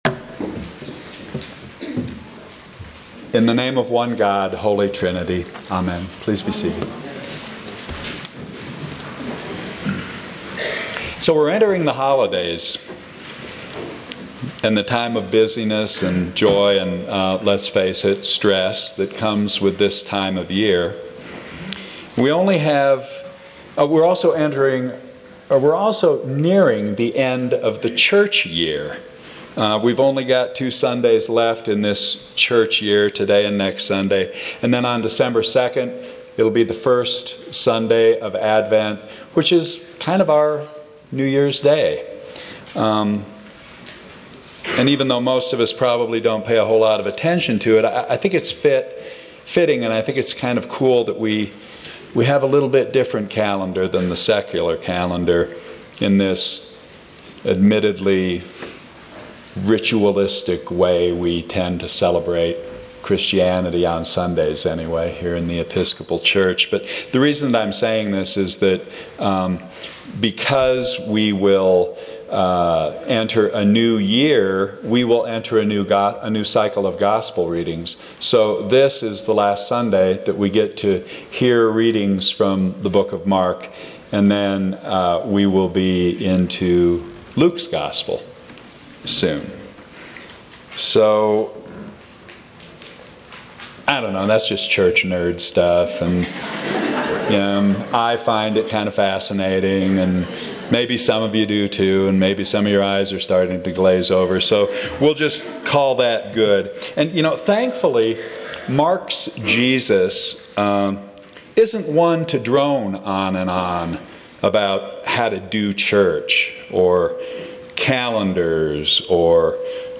Sermons from St. John's Episcopal Church Birth Pangs